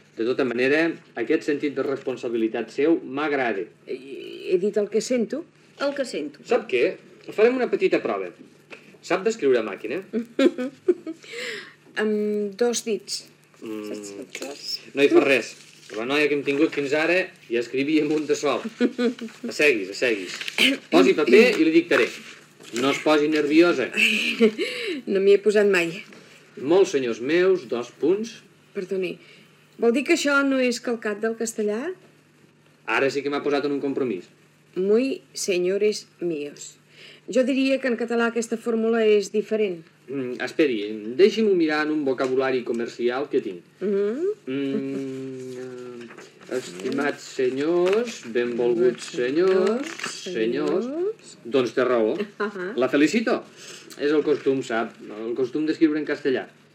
Fragment del programa on es posa en evidència una frase en català que fa un calc de la del castellà
Divulgació
Extret de "Primer aniversari de Ràdio 4" de TVE a Catalunya, emès el desembre de l'any 1977